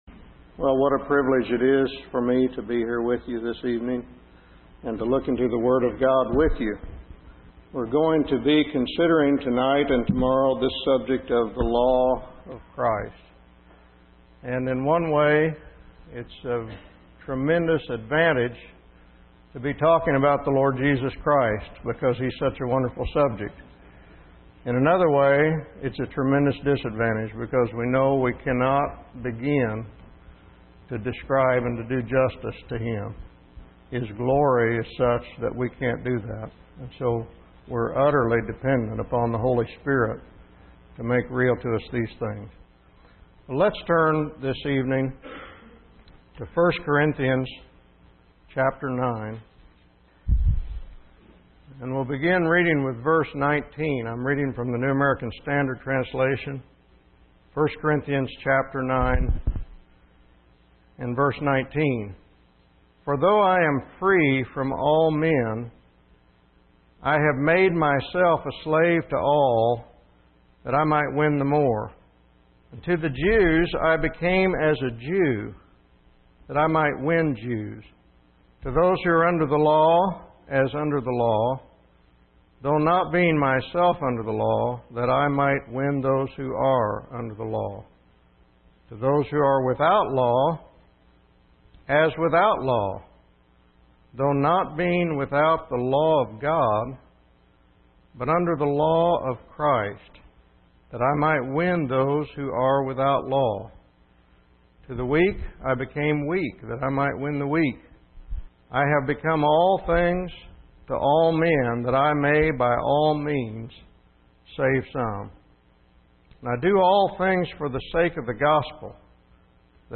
In this sermon, the speaker focuses on the parable of the vineyard from Matthew 21.